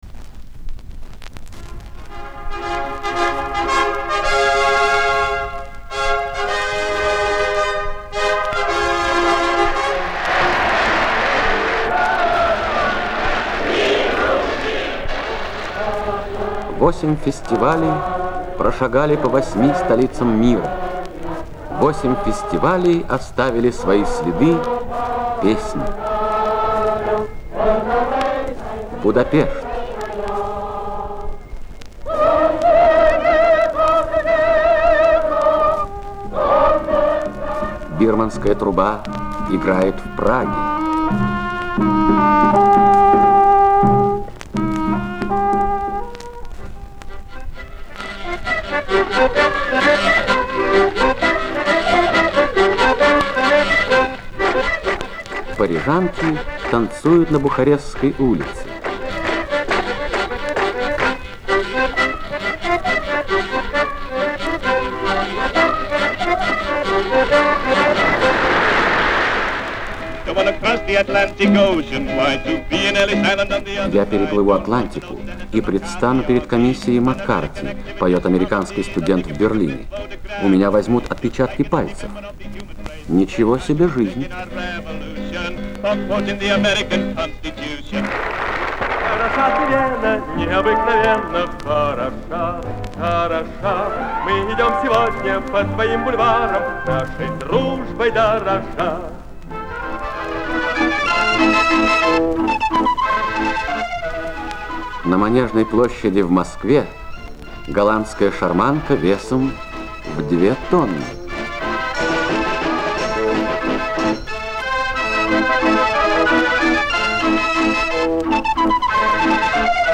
Песни темпераментные, как грузинский праздник.
Восемь человек - октет.
Молдавия: студенческий оркестр.